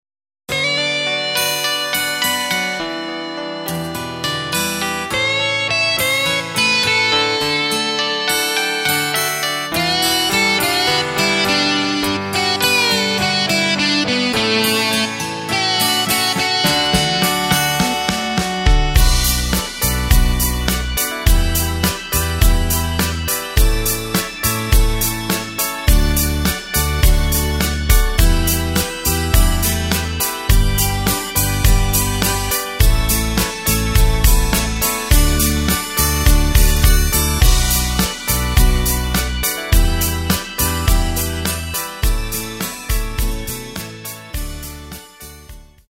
Takt:          4/4
Tempo:         104.00
Tonart:            D
Austropop aus dem Jahr 1978!
Playback mp3 Demo